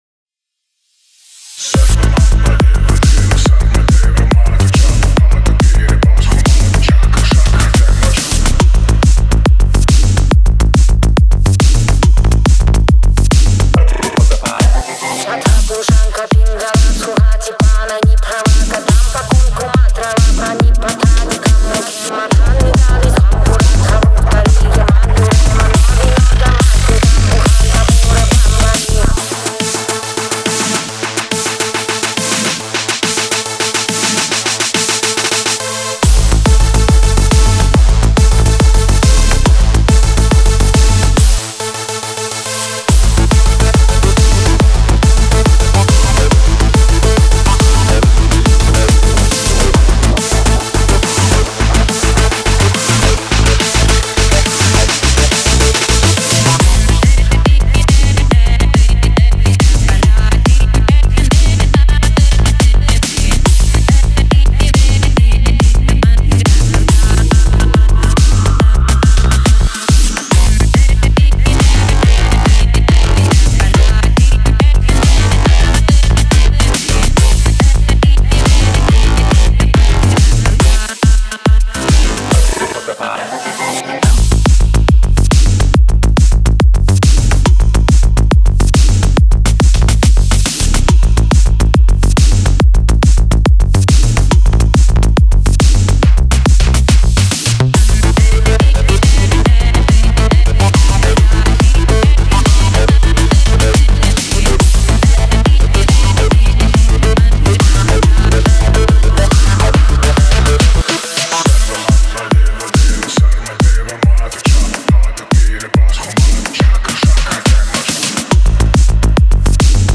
Подкину немного транса )
(По эквализации и пространству женского вокала пока очень рукожопно, позже с нуля заново буду делать) с 0:44 стилистически мимо вообще?